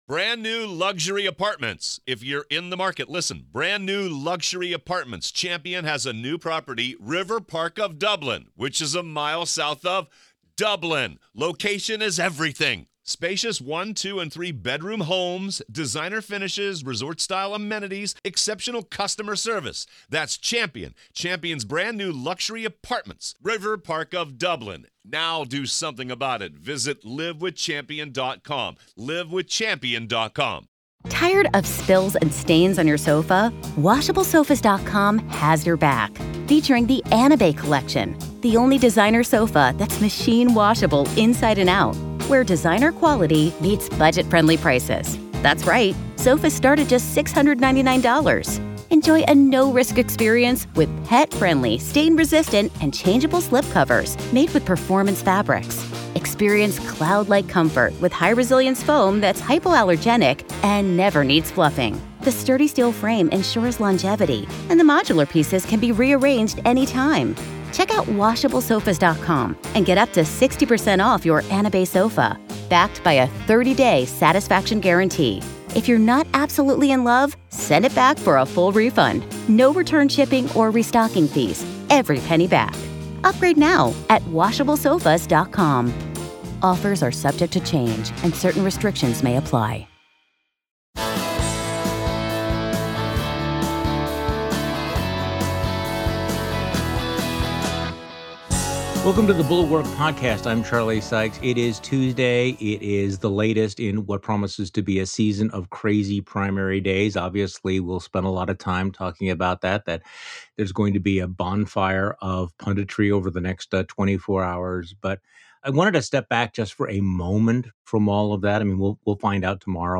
Conspiracy politics are turning evangelicals against each other as the Church becomes increasingly radicalized — an unraveling that went into overdrive during the shutdown. Tim Alberta joins Charlie Sykes today to discuss the war for the soul of the Church.